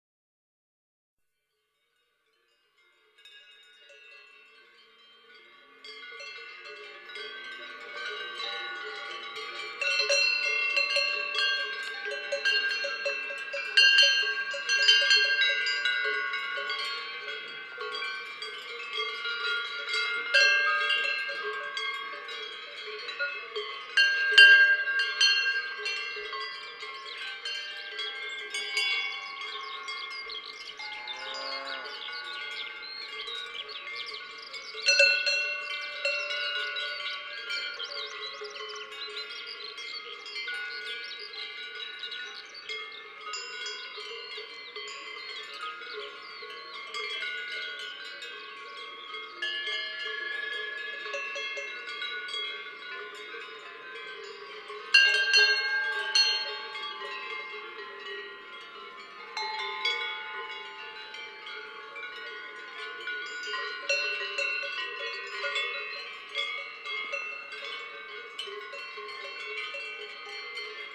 Dans les estives
Ecoutez les clarines !
01 Bells of a Herd of Cows at Sunrise on an Alpine Meadow. A Woodlark.wma